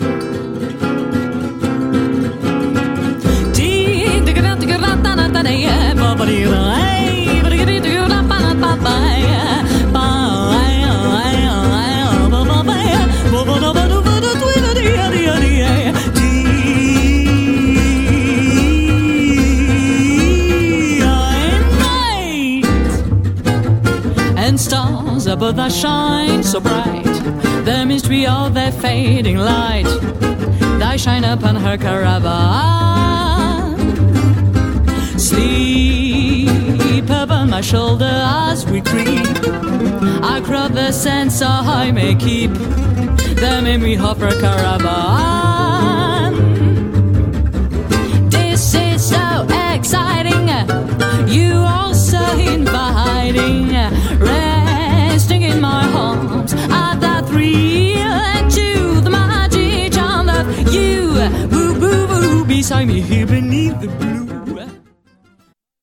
influencée par le jazz manouche
quintet